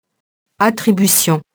attribution [atribysjɔ̃]